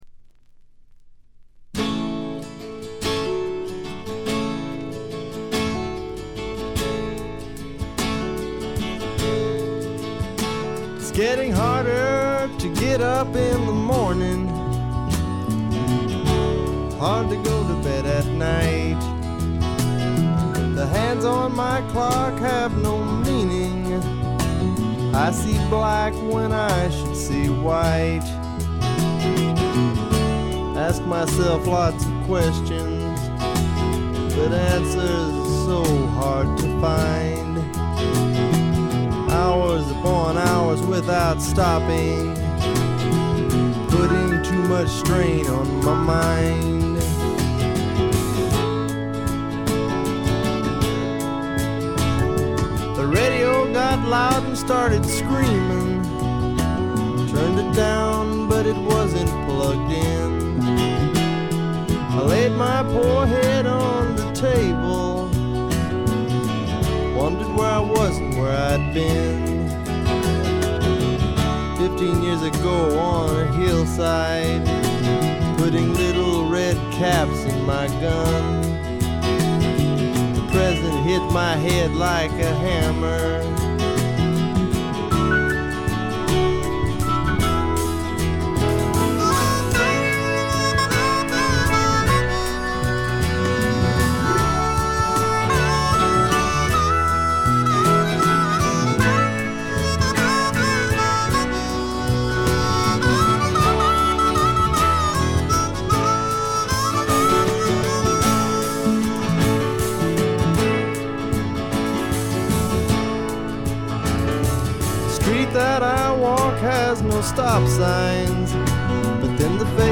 部分試聴ですがごくわずかなノイズ感のみ。
質感は哀愁のブリティッシュ・スワンプそのまんまであります。
試聴曲は現品からの取り込み音源です。